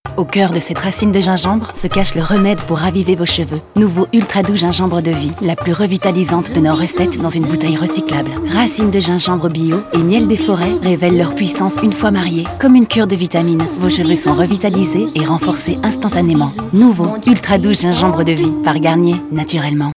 Kommerziell, Tief, Freundlich, Warm, Sanft
Unternehmensvideo